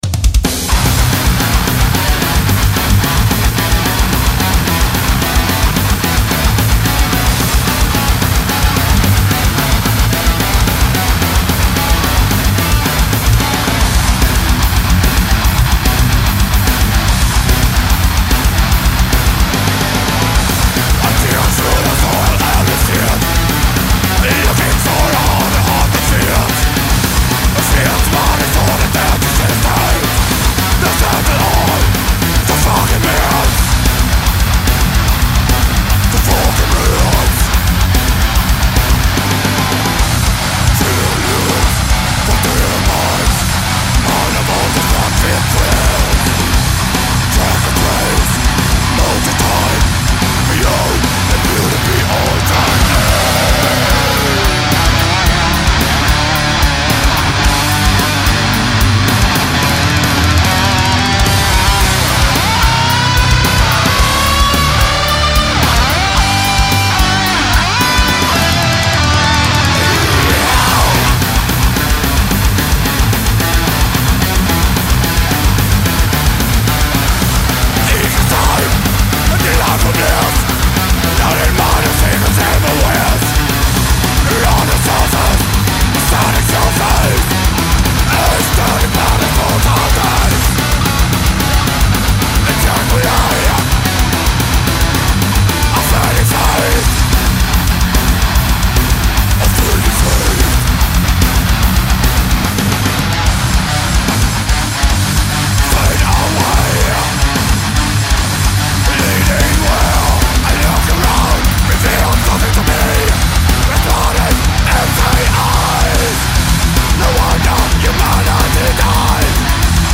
vocals
drums
guitars
bass
X-Recording, Odense, 2003